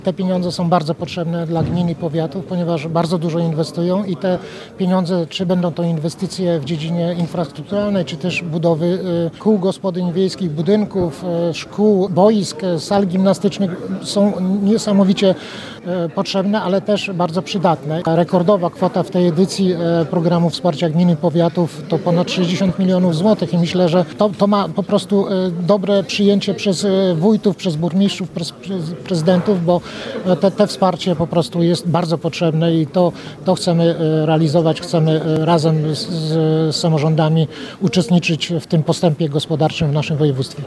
Wicemarszałek województwa podlaskiego, Marek Olbryś przyznał, że wspieranie gmin i powiatów to priorytet zarządu województwa.